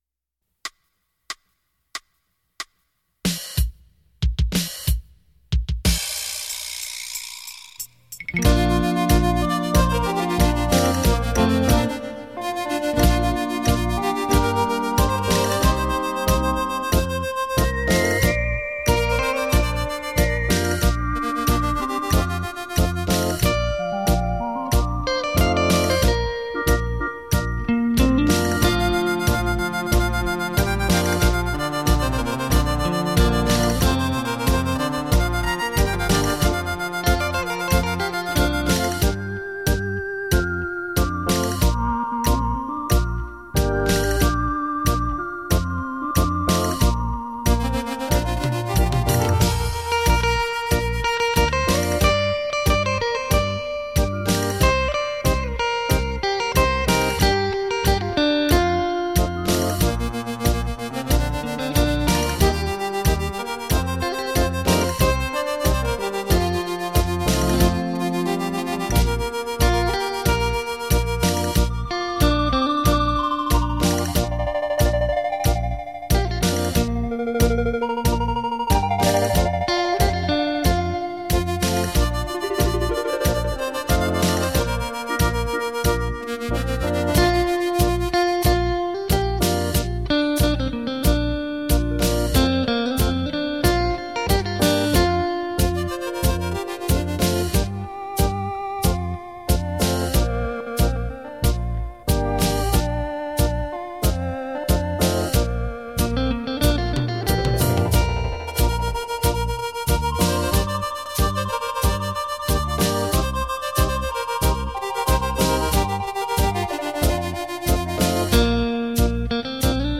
数位录音 品质保证